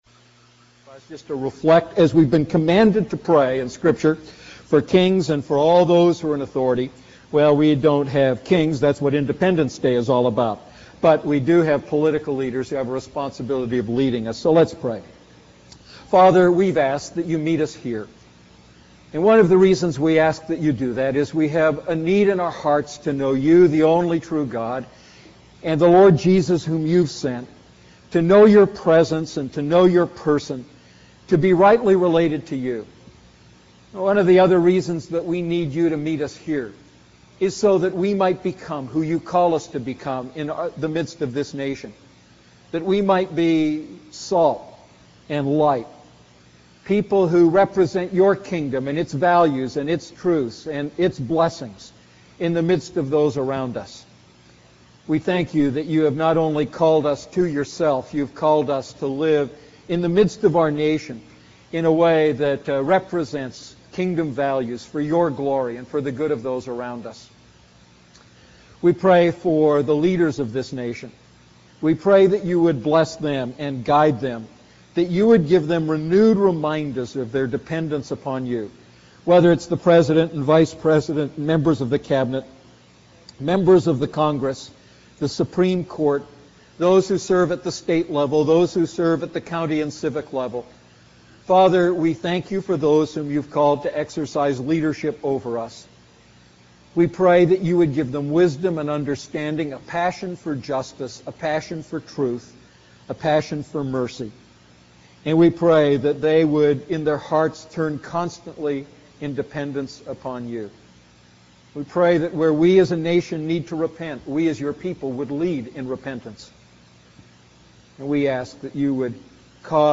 A message from the series "Holy Spirit."